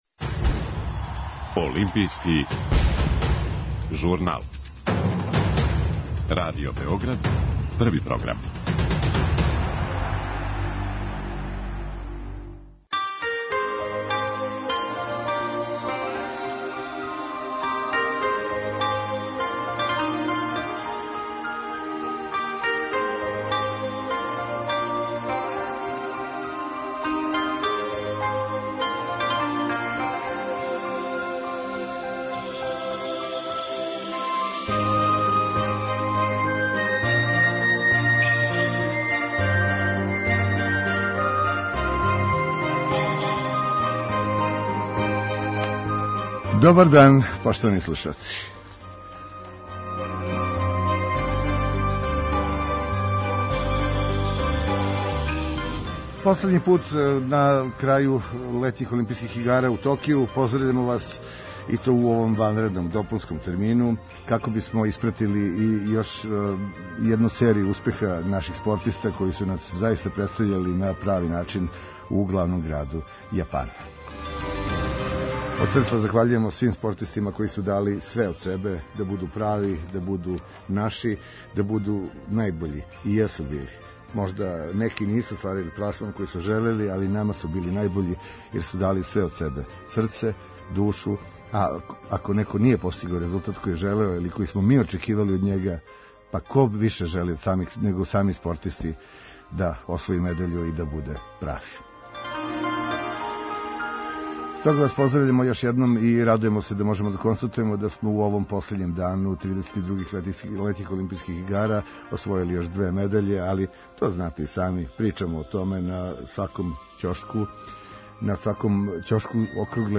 Наши репортери из Токија послаће заврше извештаје последњег дана такмичења.